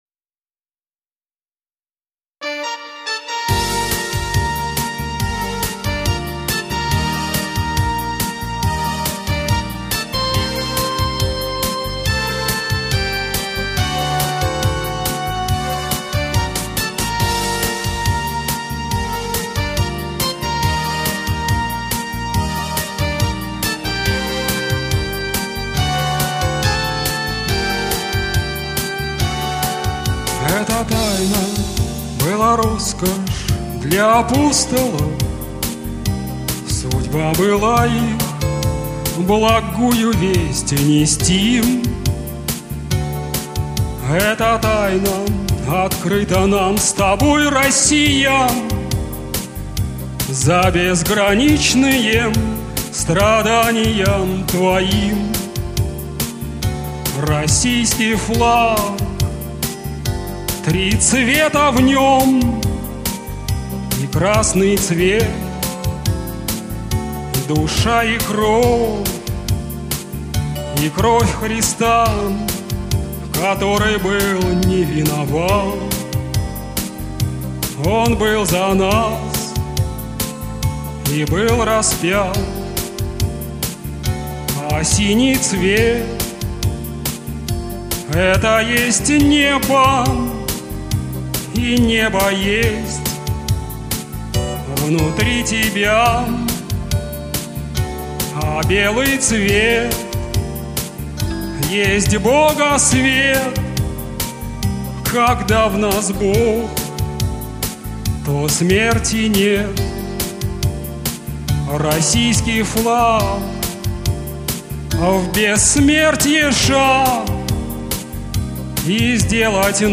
Музычка у этого великого человека, правда, совершенно лажовая - убогий "шансон" из серии "умца-умца", которую сам собой наигрывает по трем "блатным" любой современный синтезатор.